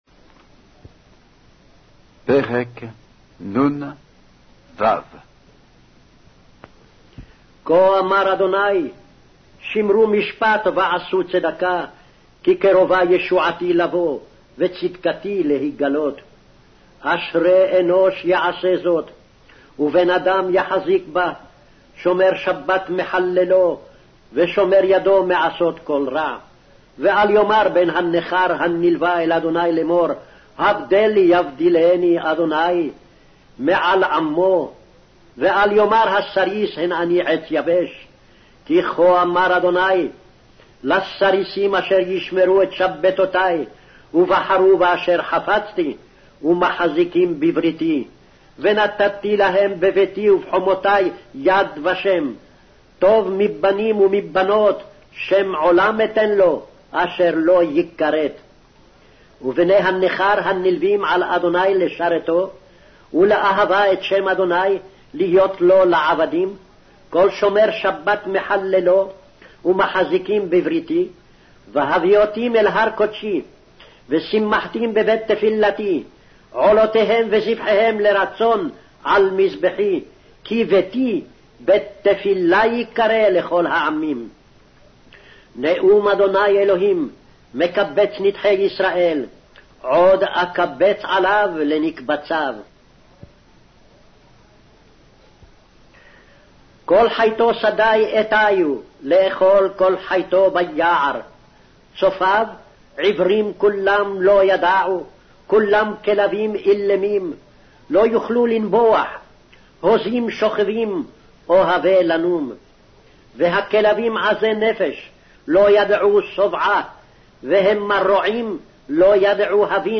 Hebrew Audio Bible - Isaiah 39 in Hov bible version